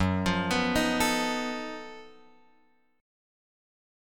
F#mM13 chord {2 x 3 4 4 5} chord